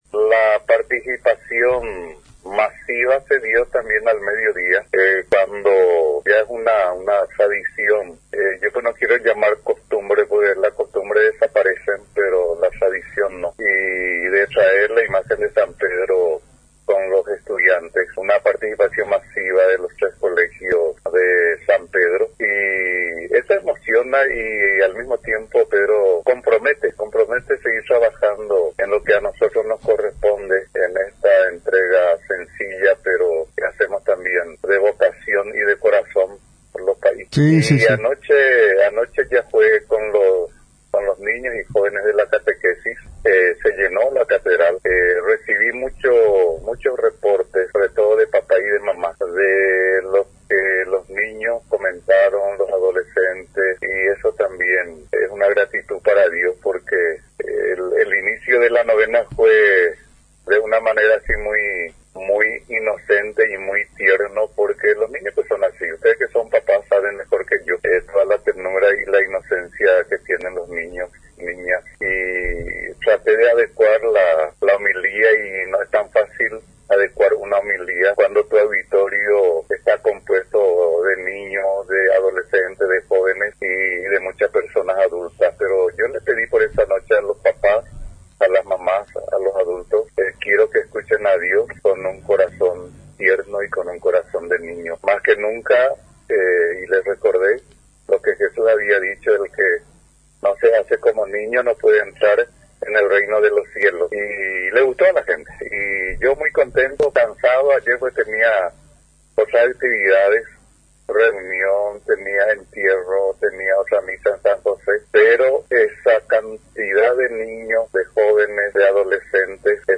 Como ya es una tradición en cada mes de junio, el miércoles en la histórica catedral se dio inicio al novenario en honor a San Pedro Apóstol, considerado como la mayor festividad religiosa en el norte del país al congregar a la mayor cantidad de los fieles del segundo departamento.